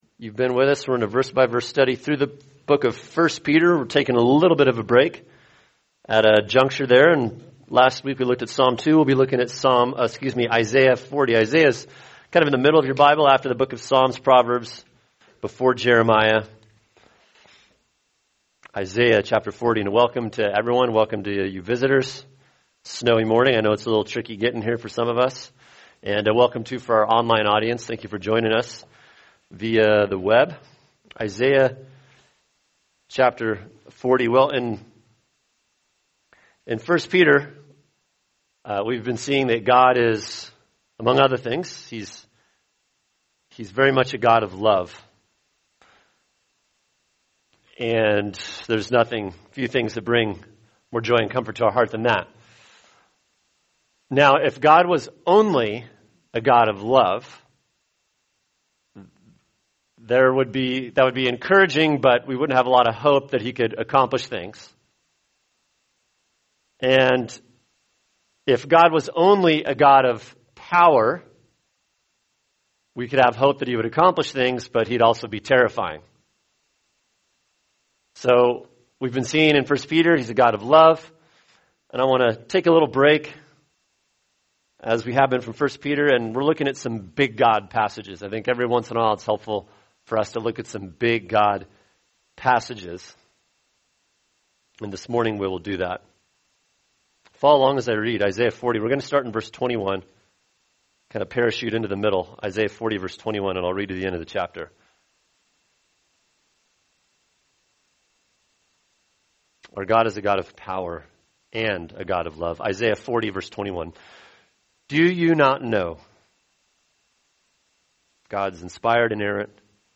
[sermon] Isaiah 40:21-31 The Glory and Comfort of God’s Omnipotence | Cornerstone Church - Jackson Hole